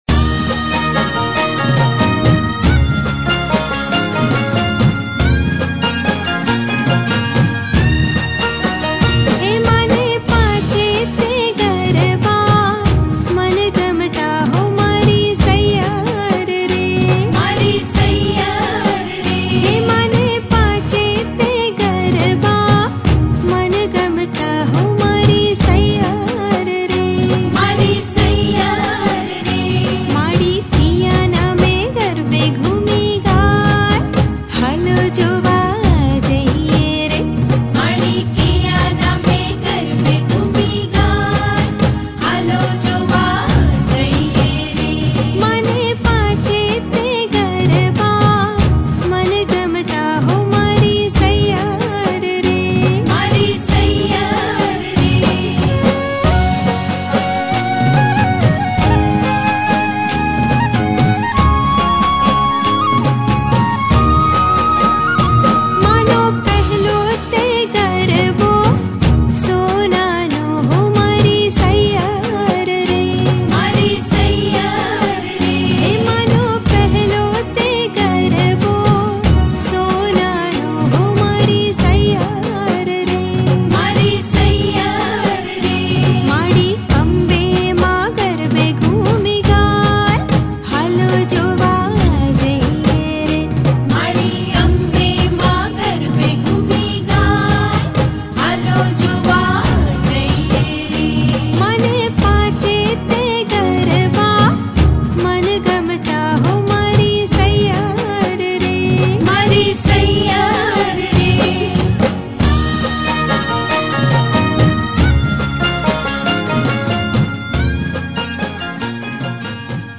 Navratri Garba